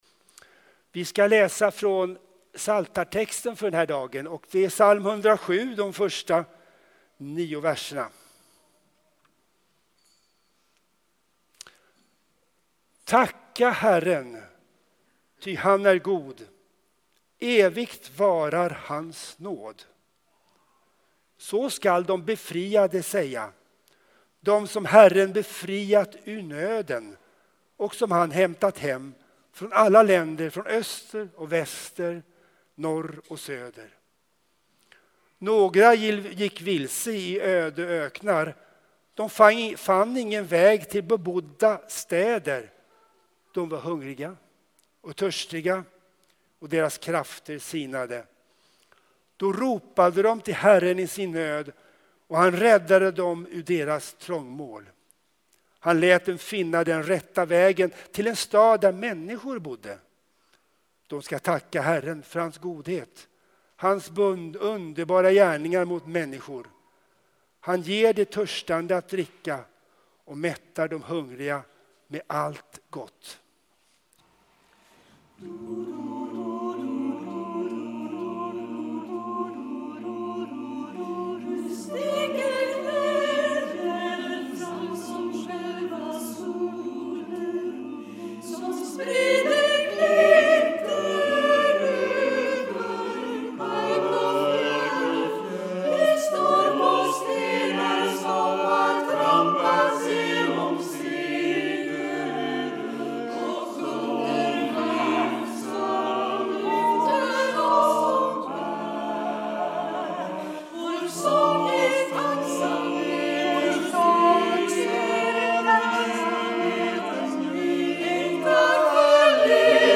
Textläsning, predikan och sång med kören ArtSong Psalm 107:1-9, Joh 6:24-35, 2 Mos 16:11-18, 2 Thess 3:16